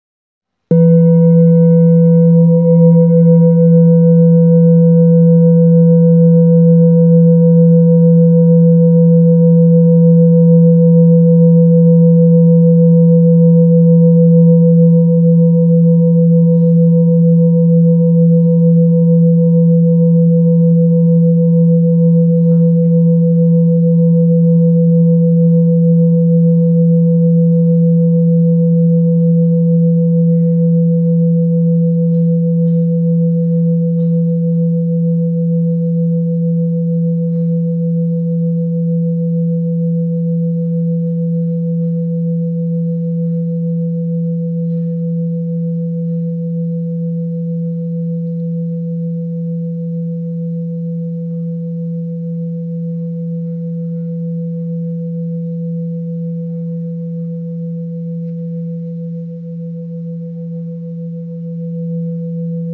Solar Chakra High Quality Singing Bowl Jambati, with Chenrezig At The Centre and Ashtamangala , A bowl used for meditation and healing, producing a soothing sound that promotes relaxation and mindfulness
Singing Bowl Ching Lu Kyogaku
Material 7 Metal Bronze